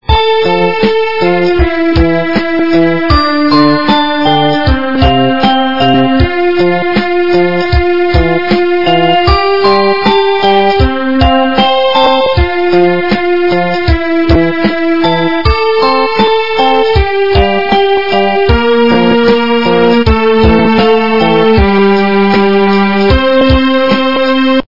западная эстрада
качество понижено и присутствуют гудки.
полифоническую мелодию